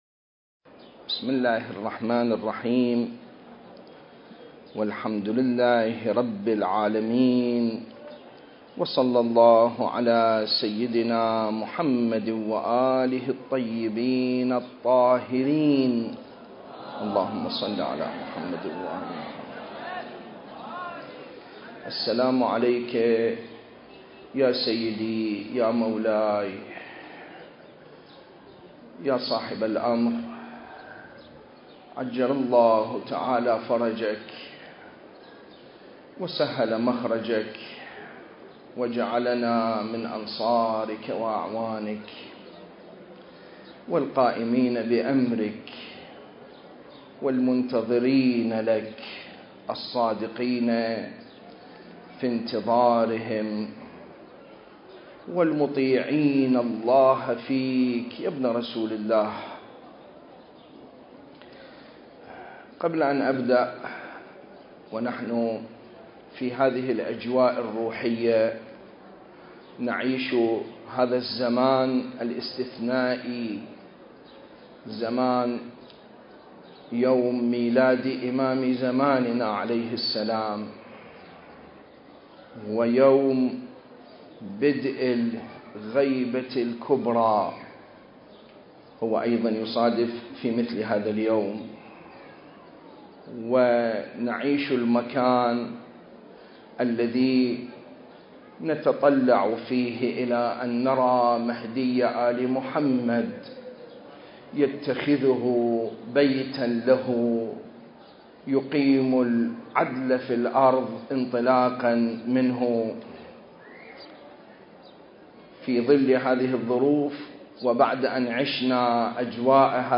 المكان: مسجد السهلة المعظم التاريخ: 2023